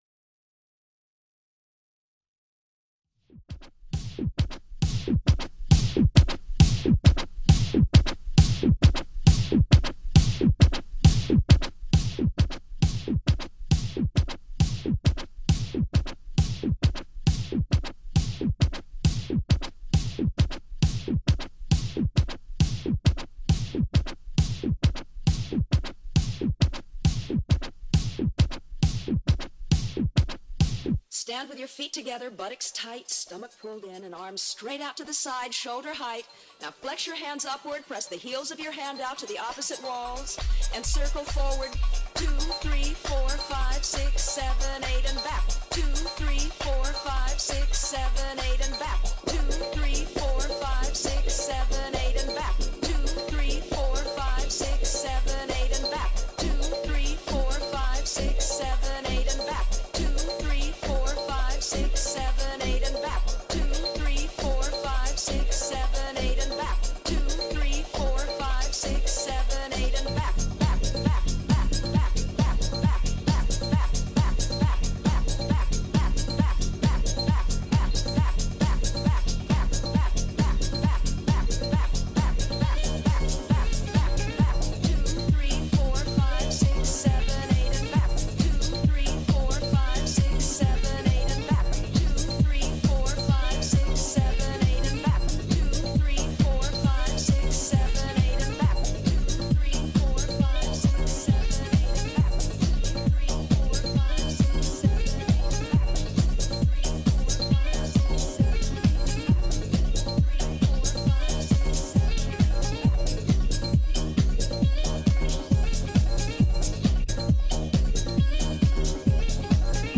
fête de la musique